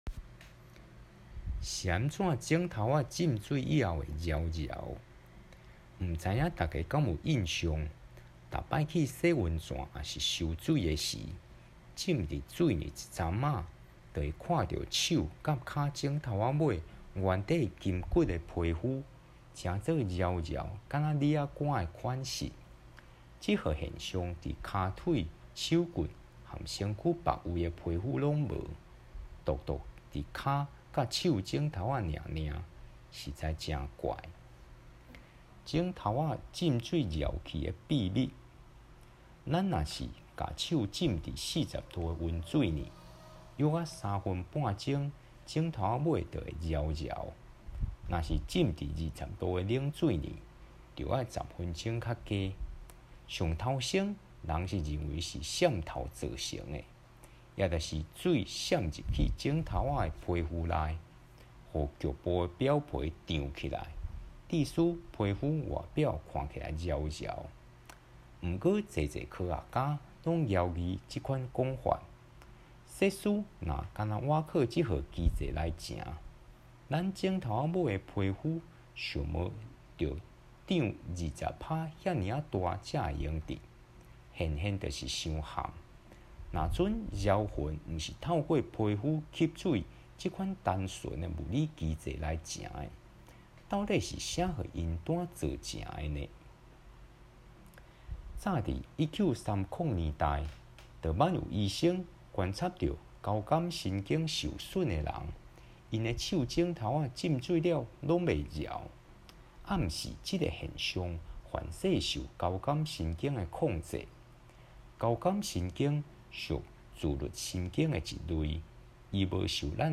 全文朗讀 Tsuân-bûn lóng-tho̍k